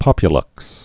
(pŏpyə-lŭks)